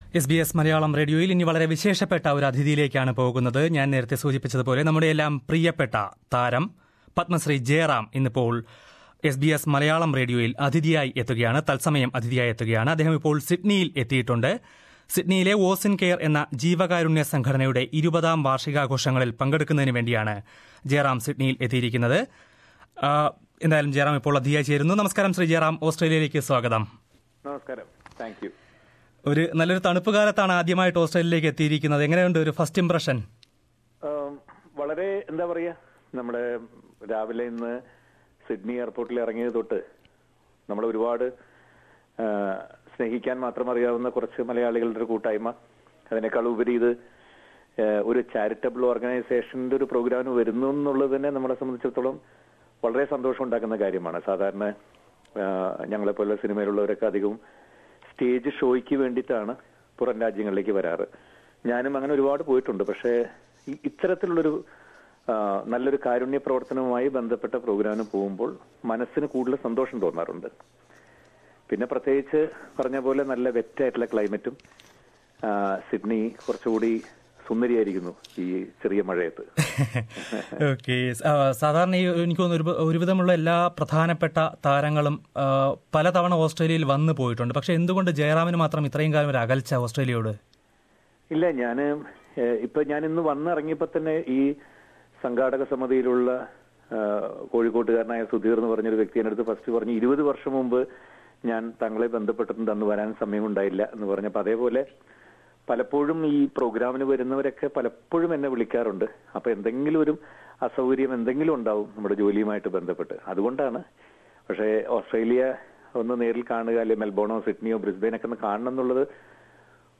Leading Malayalam actor Padmasree Jayaram has brushed aside the allegations raised by director-actor Prathah Pothen against him, saying that they don't deserve any reply. Jayaram joined the live program of SBS Malayalam during his visit to Sydney to attend the 20th anniversary program of the Sydney based charity organisation, Ozindcare. Listen to the full interview here.